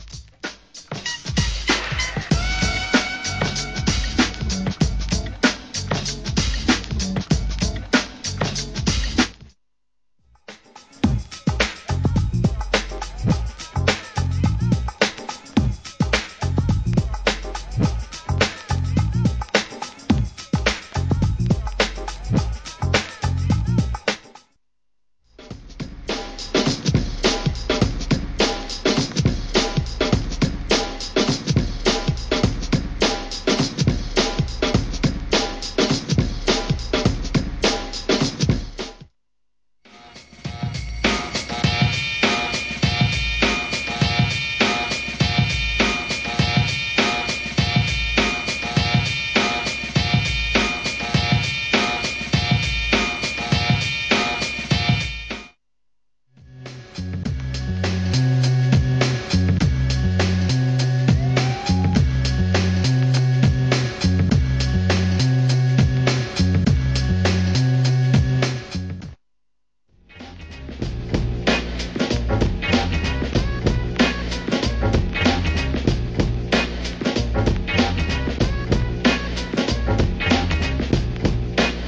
HIP HOP/R&B
硬派ブレイクビーツ第3弾